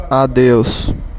Ääntäminen
IPA: /ˈnæ.ke.miːn/